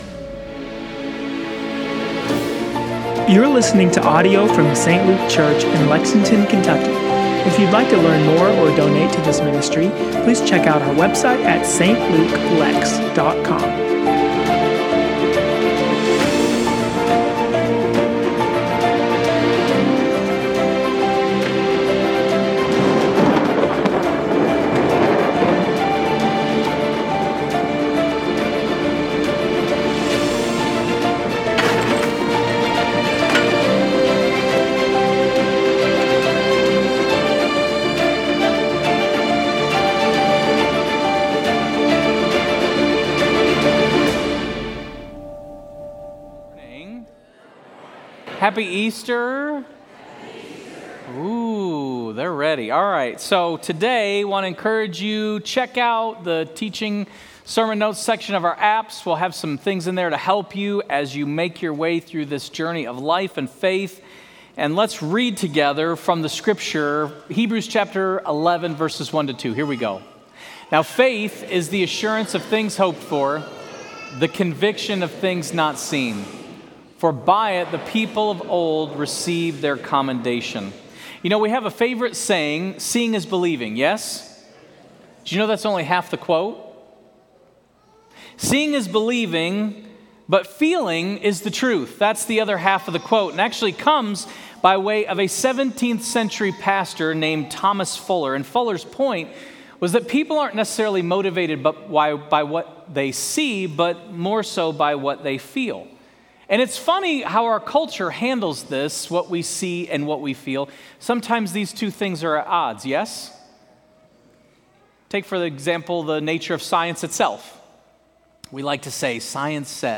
Sermon Series: Hebrews: Rediscovering Jesus